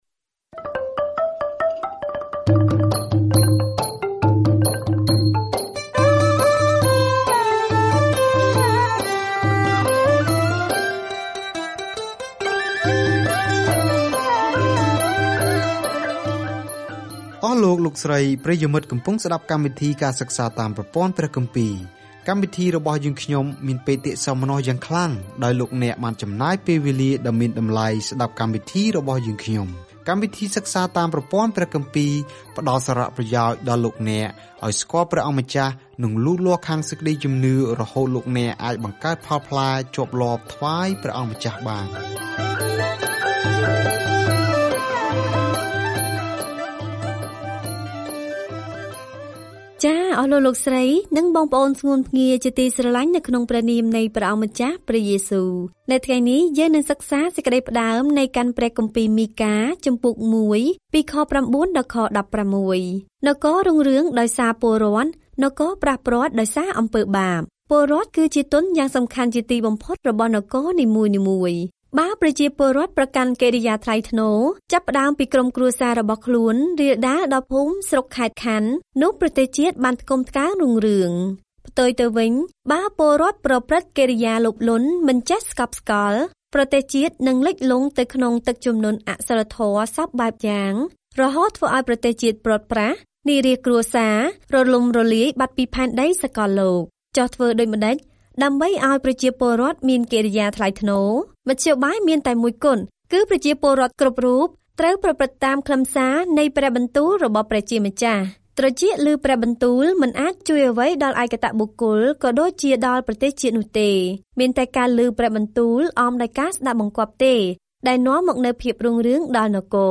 នៅក្នុងពាក្យសំដីដ៏ស្រស់ស្អាត មីកាបានហៅមេដឹកនាំនៃប្រទេសអ៊ីស្រាអែល និងយូដាឱ្យស្រឡាញ់សេចក្ដីមេត្ដាករុណា ប្រព្រឹត្តដោយយុត្ដិធម៌ ហើយដើរដោយបន្ទាបខ្លួនជាមួយនឹងព្រះ។ ការធ្វើដំណើរជារៀងរាល់ថ្ងៃតាមរយៈមីកា ពេលអ្នកស្តាប់ការសិក្សាជាសំឡេង ហើយអានខគម្ពីរដែលជ្រើសរើសពីព្រះបន្ទូលរបស់ព្រះ។